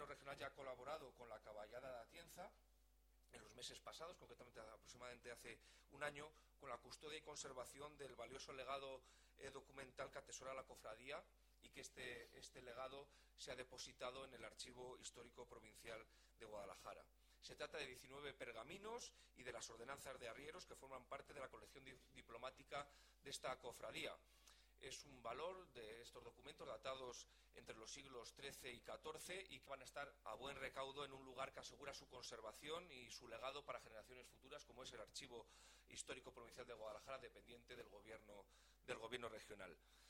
El delegado de la Junta en Guadalajara, Alberto Rojo, habla de la colaboración del Gobierno regional en la conservación del legado documental de La Caballada.